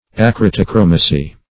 Search Result for " acritochromacy" : The Collaborative International Dictionary of English v.0.48: Acritochromacy \Ac`ri*to*chro"ma*cy\, n. [Gr.